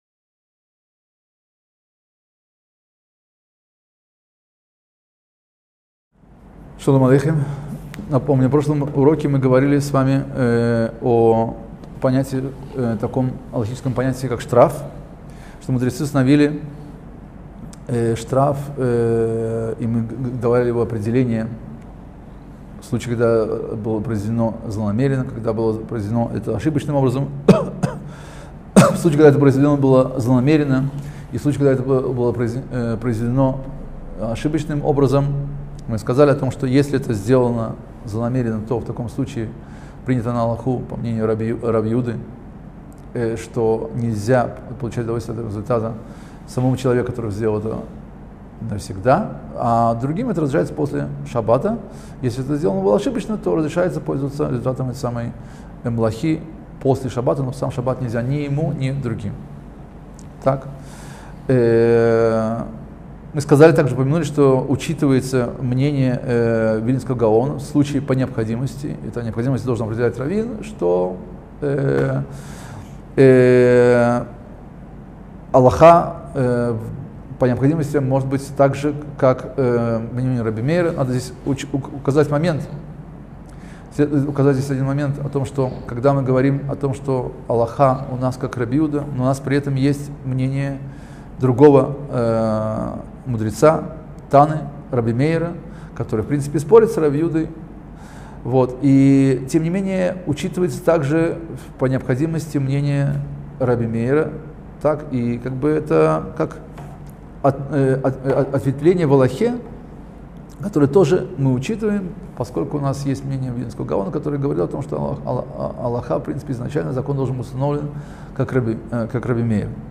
Краткие уроки по законам Субботы.